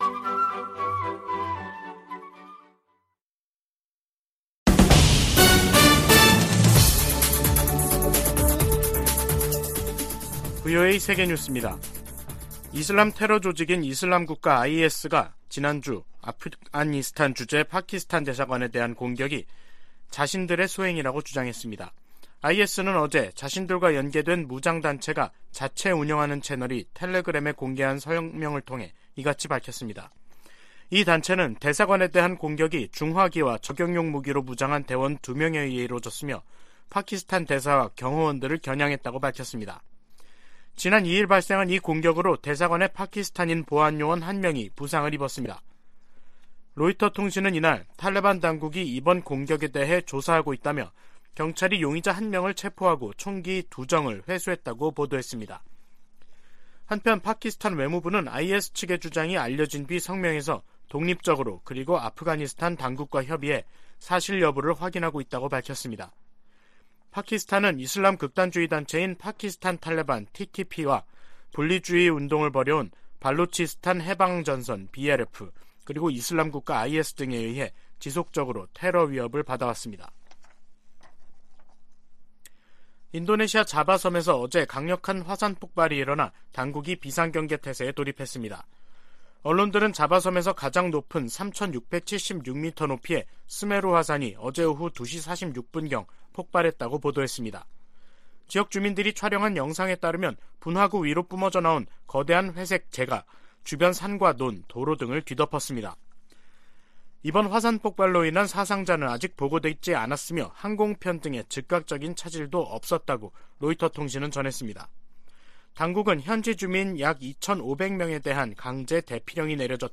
VOA 한국어 간판 뉴스 프로그램 '뉴스 투데이', 2022년 12월 5일 2부 방송입니다. 북한이 오늘 동해와 서해상으로 남북 군사합의를 위반하는 무더기 포 사격을 가했습니다. 백악관 고위 당국자가 북한의 핵 기술과 탄도미사일 역량 발전이 누구의 이익에도 부합하지 않는다면서 중국과 러시아에 적극적인 제재 동참을 촉구했습니다.